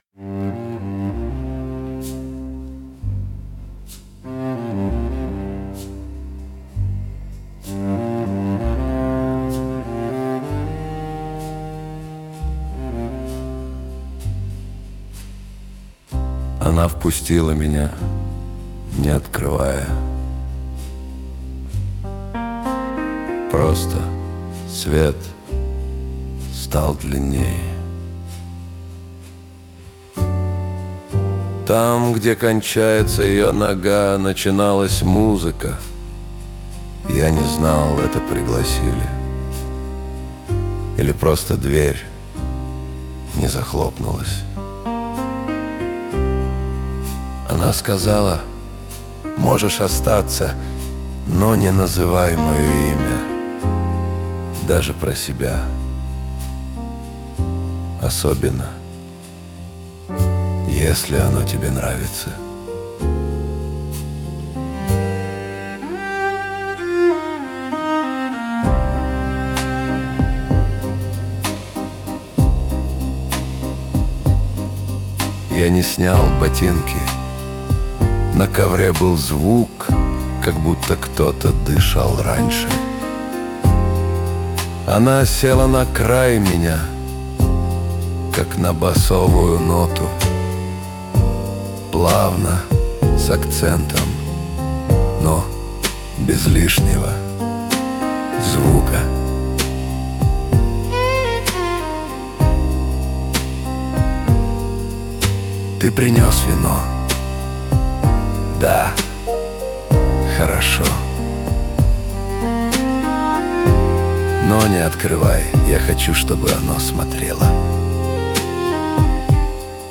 Разговорное слово в стиле Сержа Генсбура